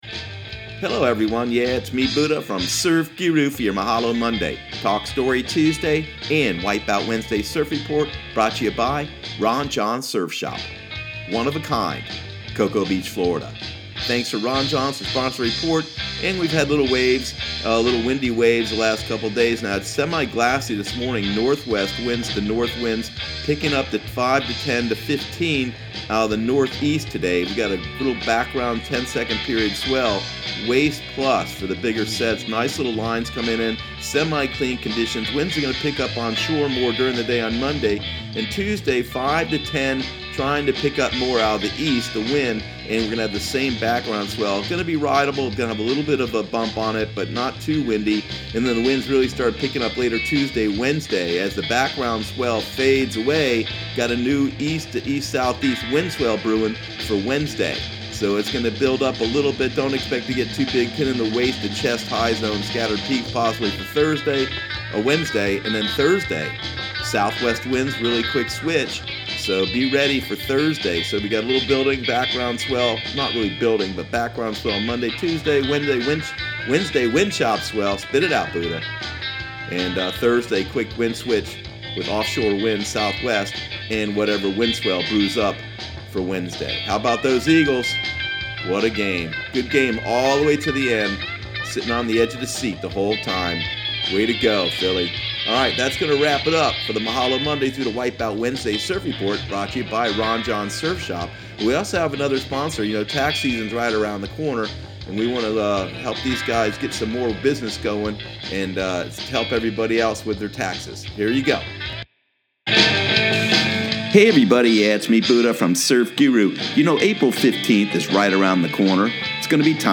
Surf Guru Surf Report and Forecast 02/05/2018 Audio surf report and surf forecast on February 05 for Central Florida and the Southeast.